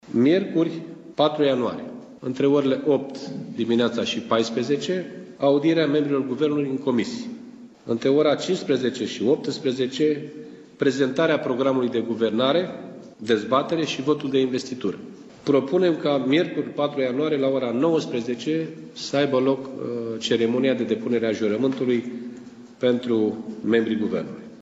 Președintele social-democraților, Liviu Dragnea, a susținut, astăzi, o conferință de presă alături de co-președintele ALDE, Călin Popescu Tăriceanu și de premierul desemnat, Sorin Grindeanu.